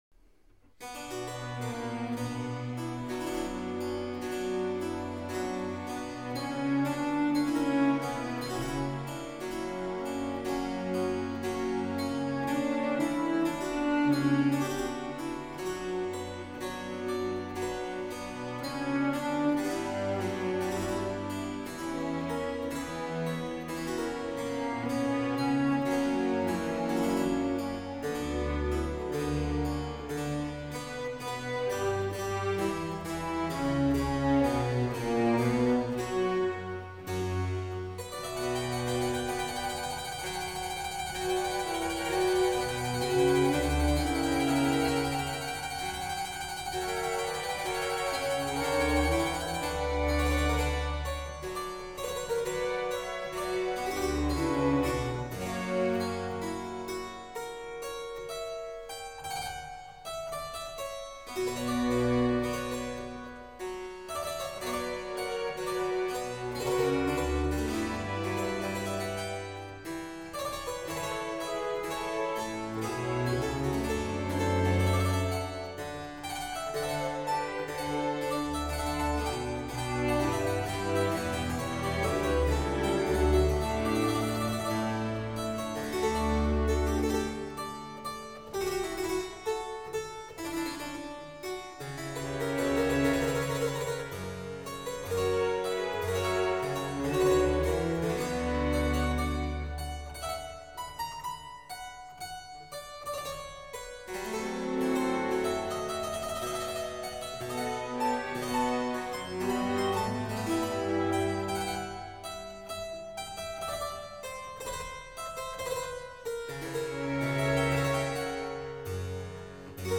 J. S. Bach, Harpsichord Concerto No. 3 in D major, BWV 1054_ Adagio e piano sempre